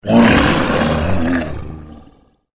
troll_commander_die.wav